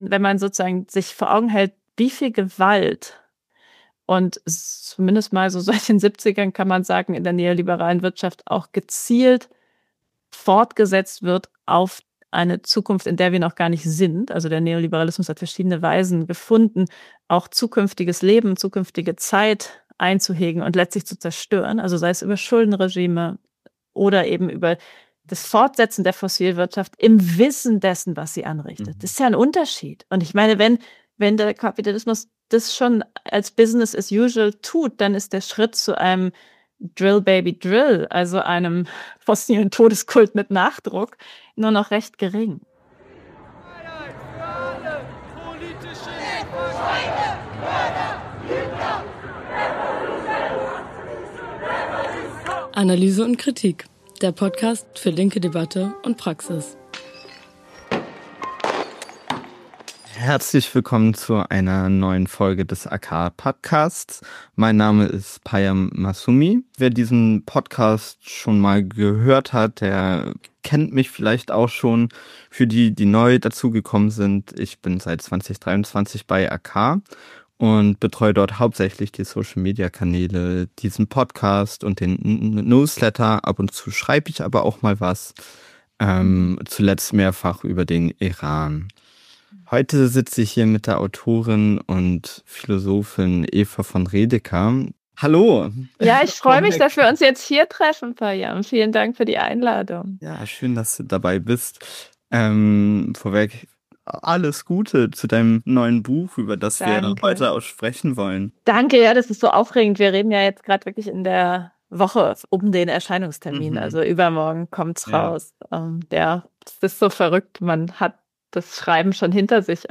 Stattdessen droht eine Rückkehr zum Recht des Stärkeren. Ist das schon Faschismus? Darüber haben wir mit der Philosophin und Autorin Eva von Redecker gesprochen, die in ihrem neuesten Buch "Dieser Drang nach Härte" über den neuen Faschismus nachdenkt.